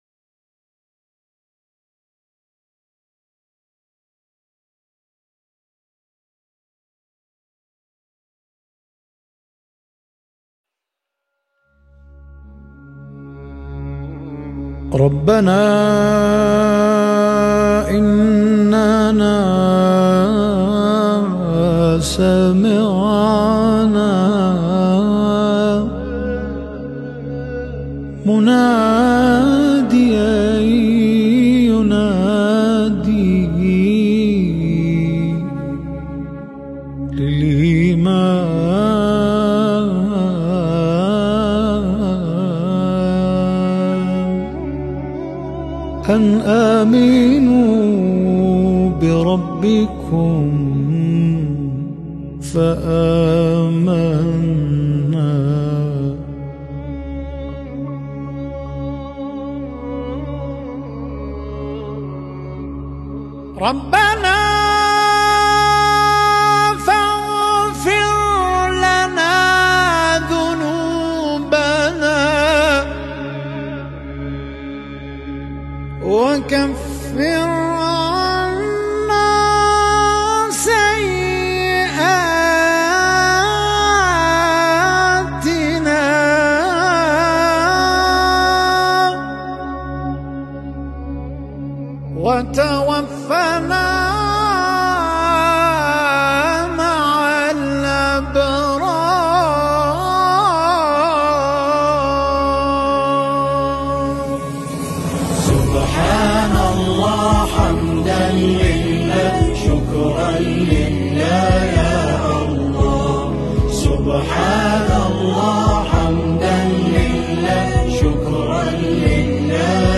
نماهنگ «ربنا انّنا سمعنا